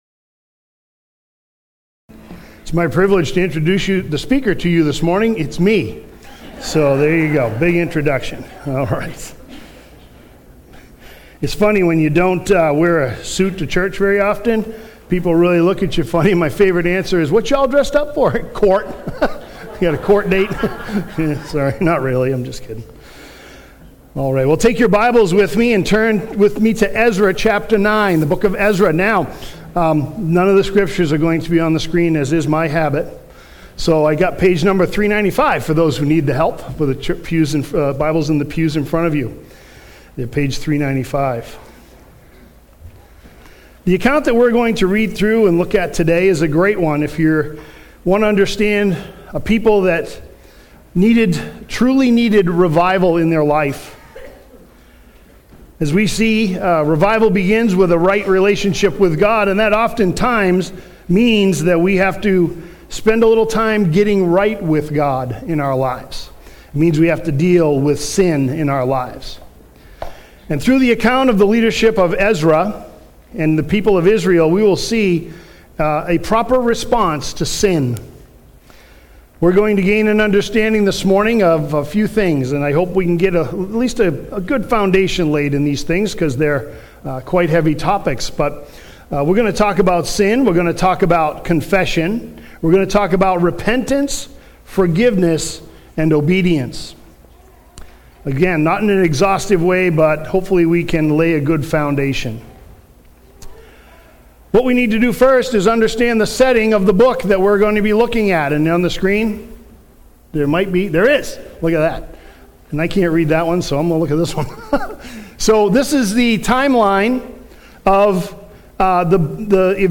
sermon
Sermon-6-8-25-MP3-for-Audio-Podcasting.mp3